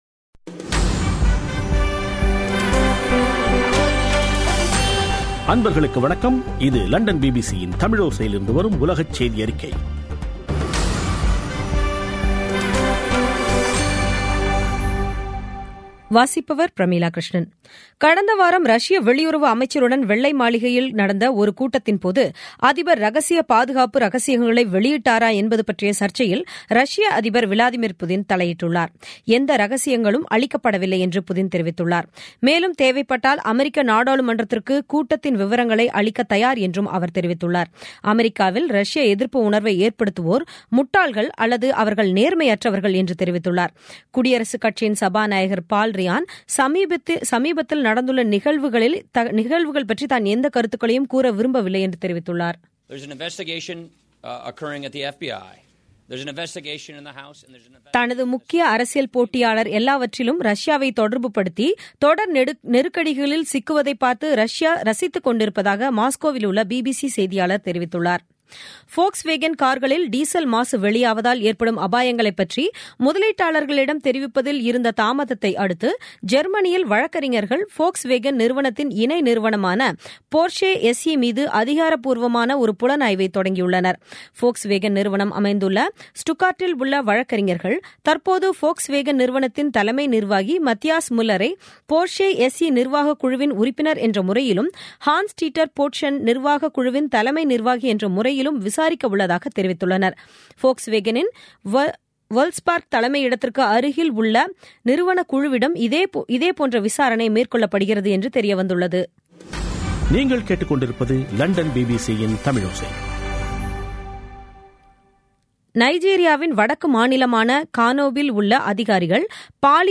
பிபிசி தமிழோசை செய்தியறிக்கை (17/05/2017)